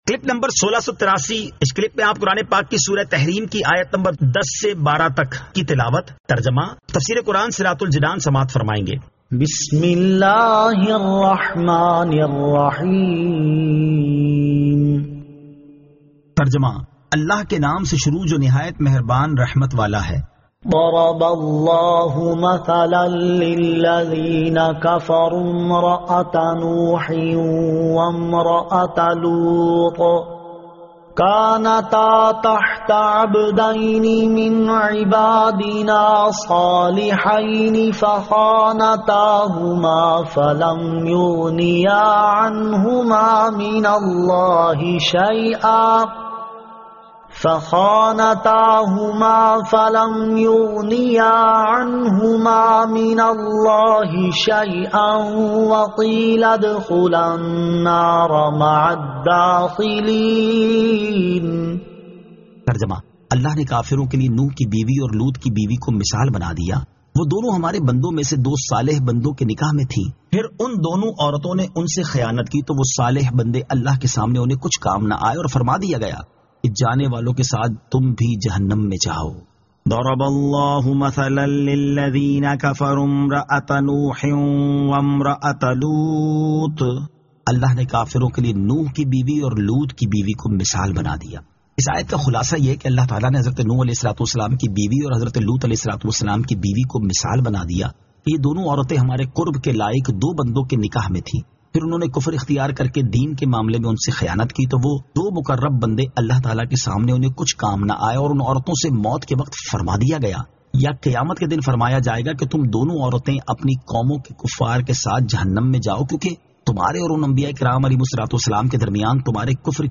Surah At-Tahrim 10 To 12 Tilawat , Tarjama , Tafseer